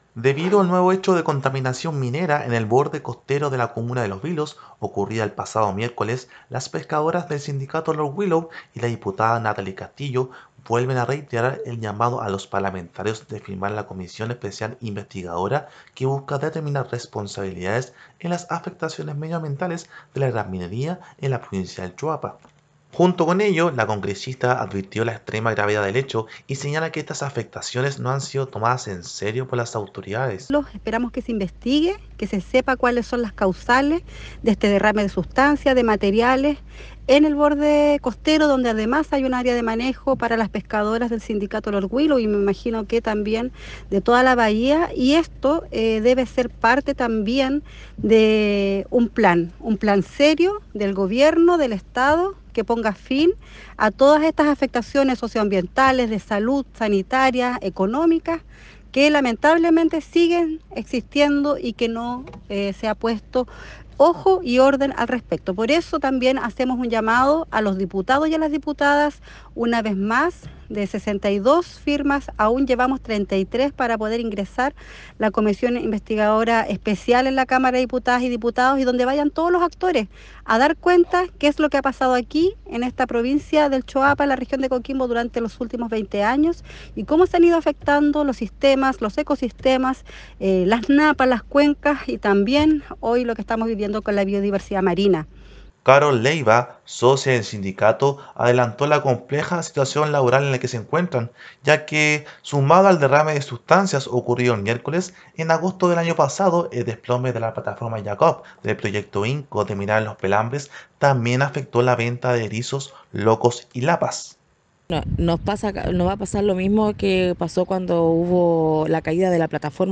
Despacho-radial-Contaminacion-y-CEI.mp3